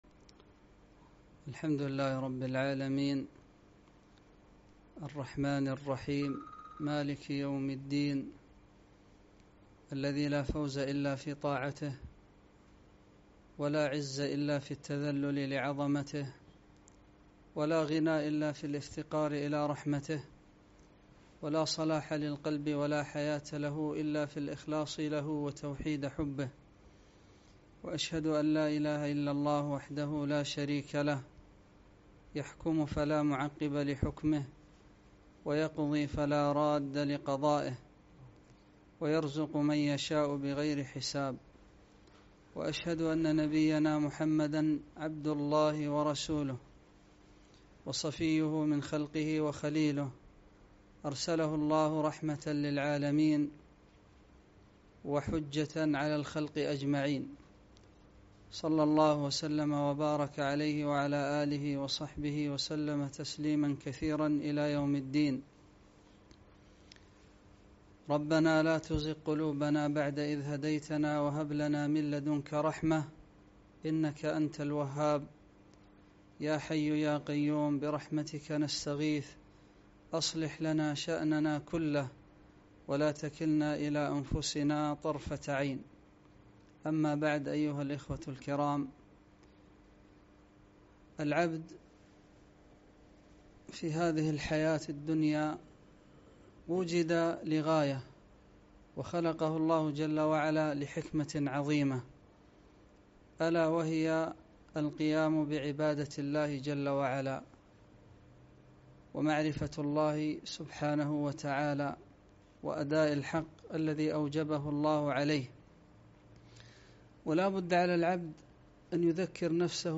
محاضرة - أنواع محاسبة النفس